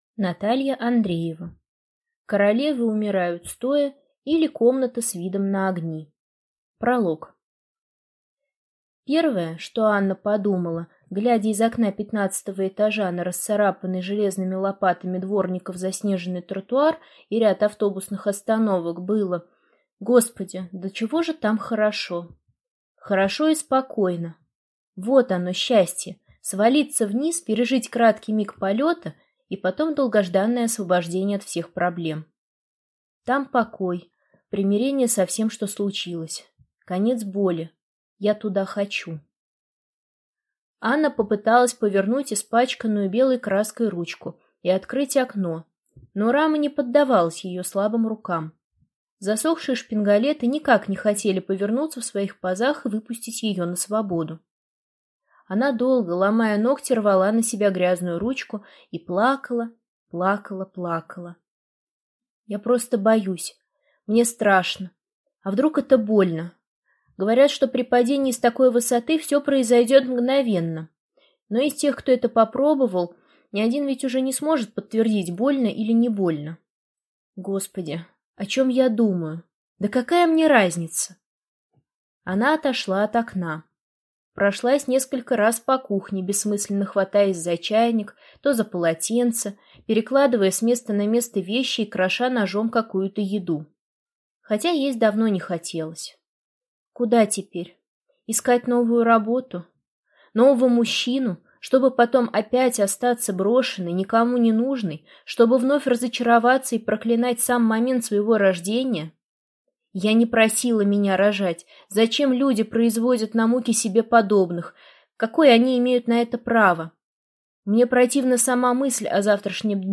Аудиокнига Королевы умирают стоя, или Комната с видом на огни | Библиотека аудиокниг